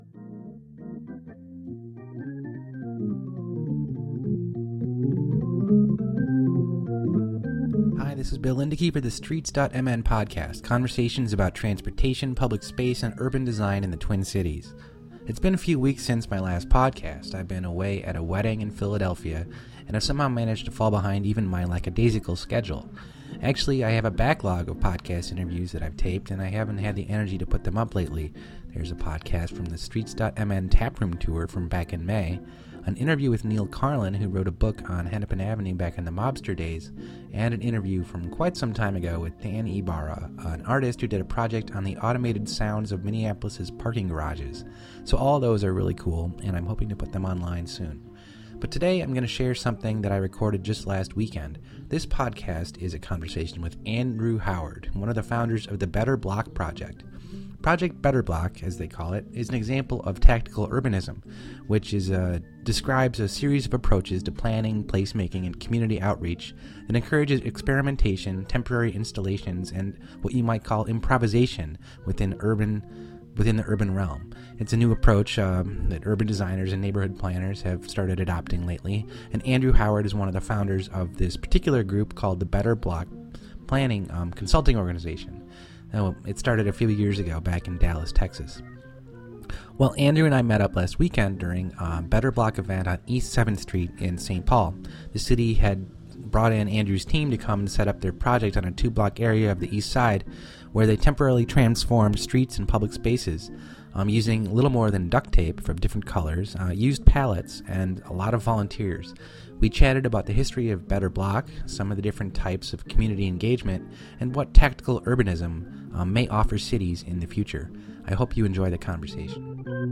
This podcast this week is a conversation